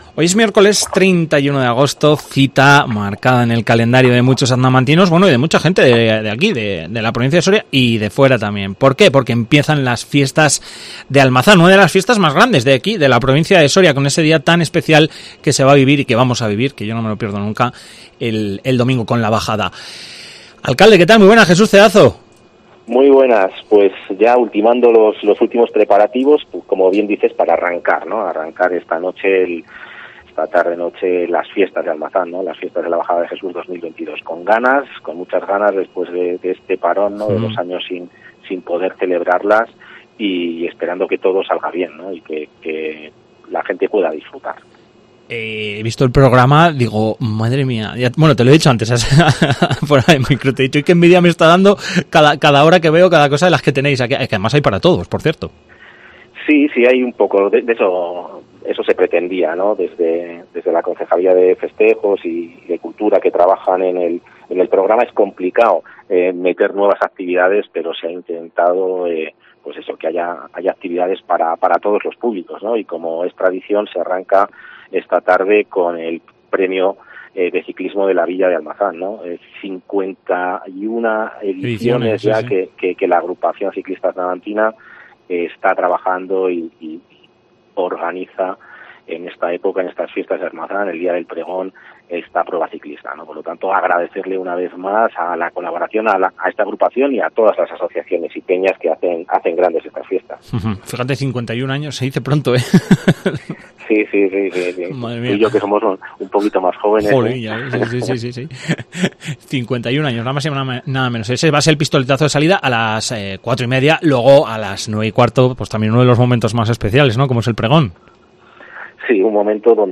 Comienzan las fiestas de Almazán, hablamos con su alcalde, Jesús Cedazo